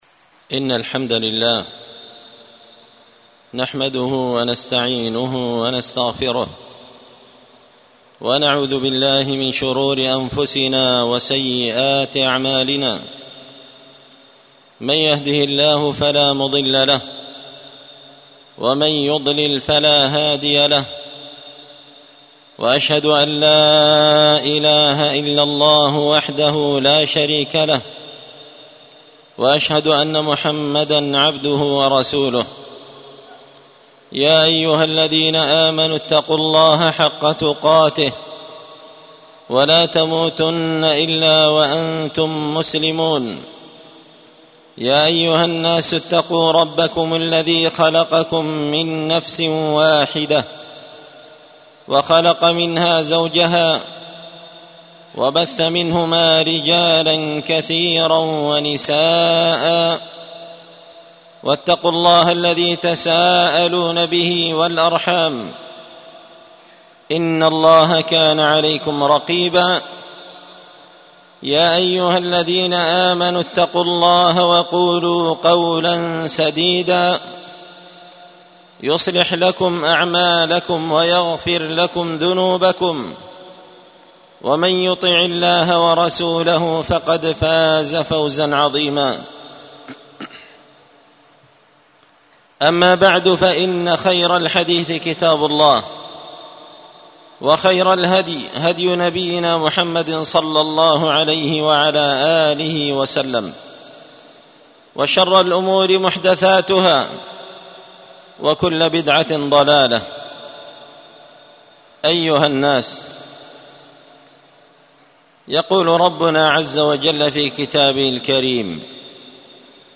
خطبة جمعة بعنوان: *{تحذير الخليل من نكران الجميل}* *💺للشيخ